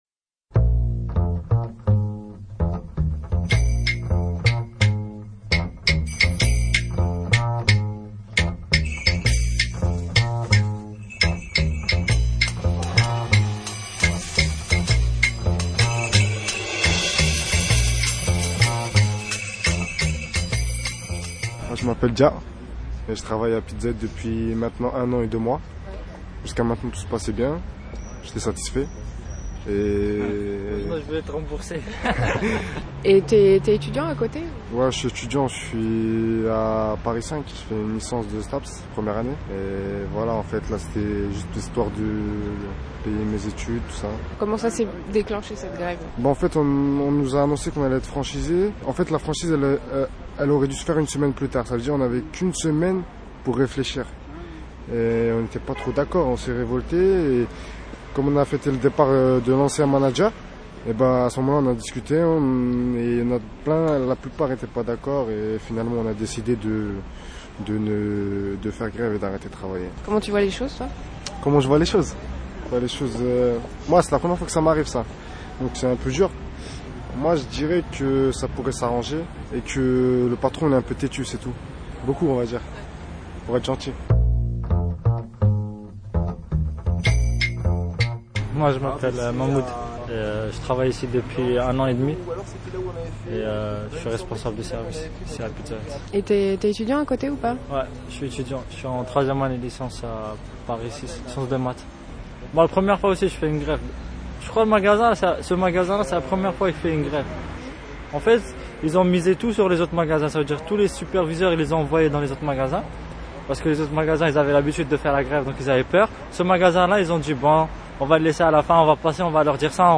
Ce reportage